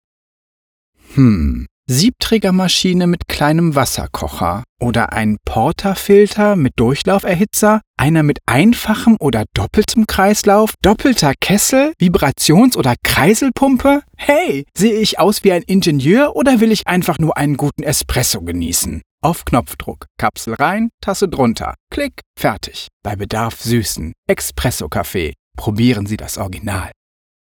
Deutscher Sprecher, helle Stimme, Werbesprecher, Schauspieler, Synchronsprecher
Sprechprobe: Werbung (Muttersprache):
Demo_Werbung_XPresso.mp3